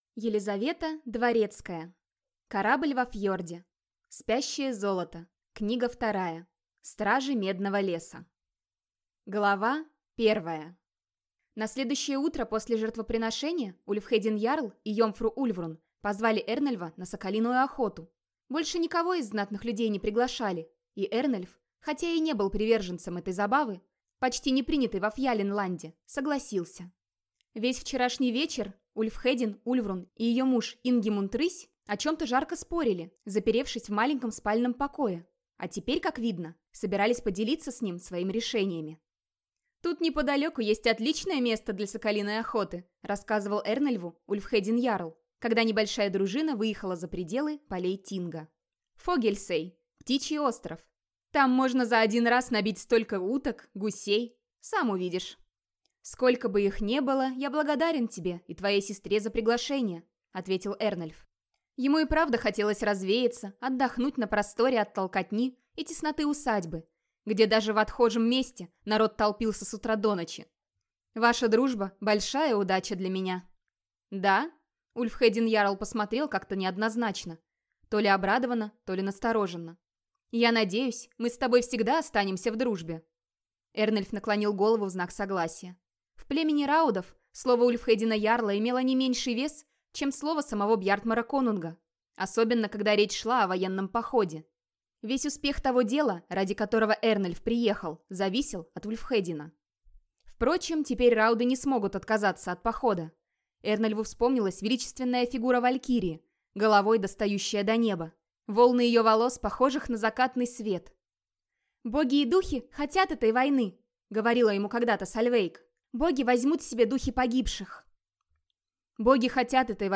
Аудиокнига Спящее золото. Книга 2: Стражи Медного леса | Библиотека аудиокниг